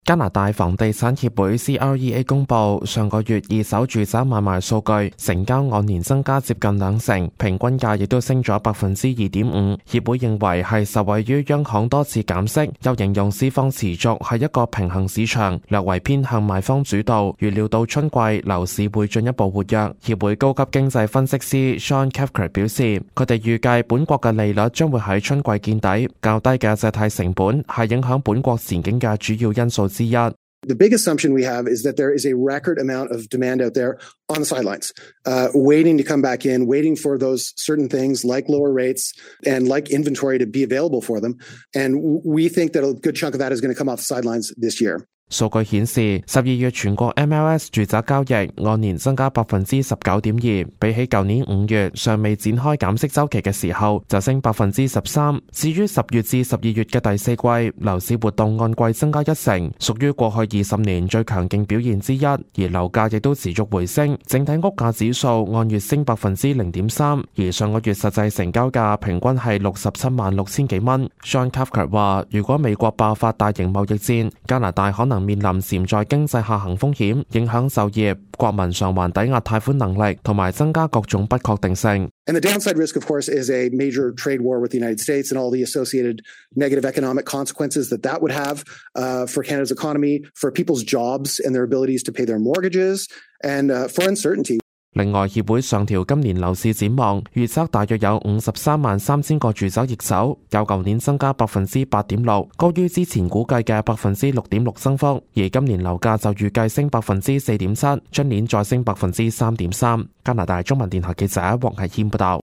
news_clip_22158.mp3